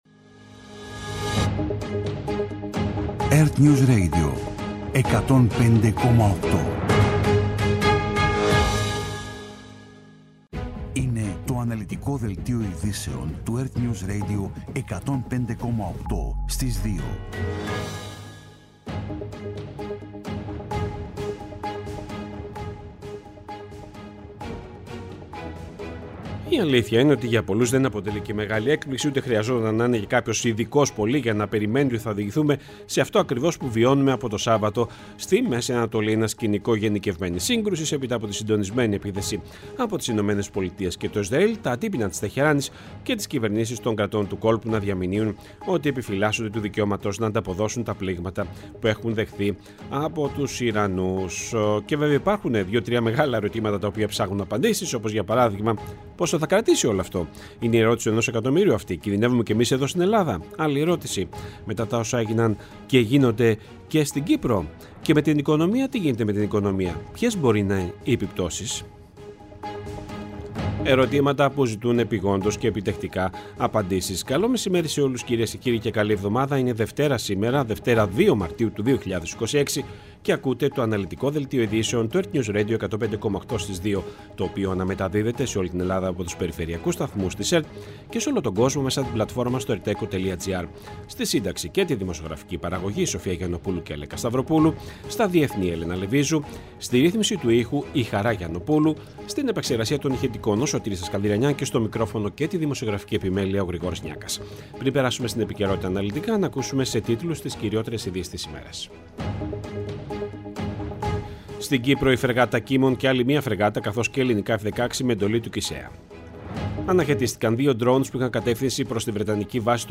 Το αναλυτικό ενημερωτικό μαγκαζίνο στις 14:00.
Με το μεγαλύτερο δίκτυο ανταποκριτών σε όλη τη χώρα, αναλυτικά ρεπορτάζ και συνεντεύξεις επικαιρότητας.